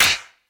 Claps
Baby Clap.wav